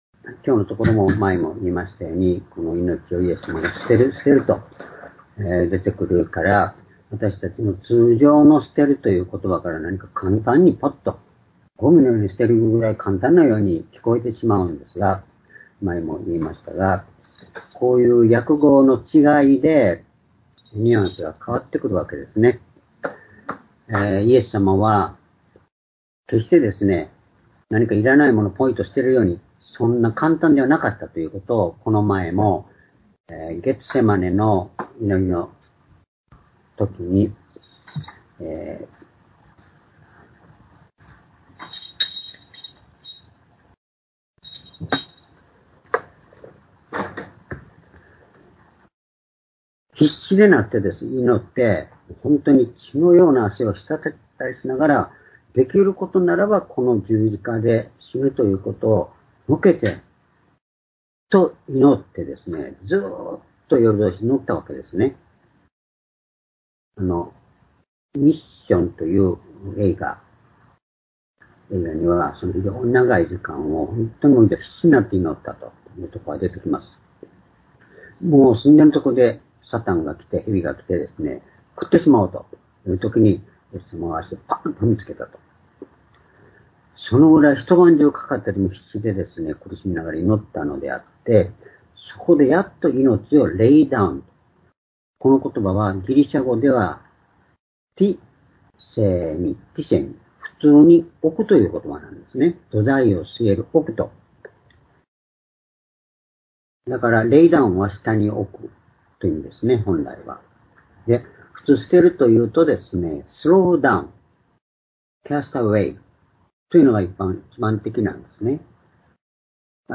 主日礼拝日時 2023年6月１８日（主日礼拝） 聖書講話箇所 「主にあって一つとなること」 ヨハネ10の16-18 ※視聴できない場合は をクリックしてください。